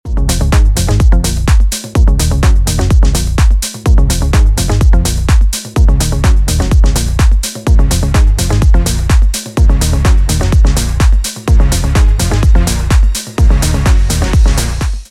• Качество: 320, Stereo
ритмичные
веселые
без слов
быстрые
house
80-е
Зажигательный ритм в стиле 80-ых